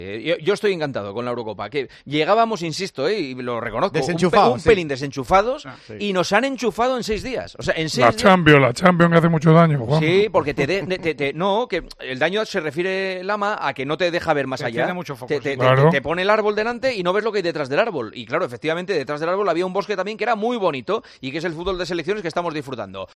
El director de El Partidazo de COPE analizó este martes junto al equipo habitual de comentaristas la intensa primera jornada de la fase de grupos de la Eurocopa.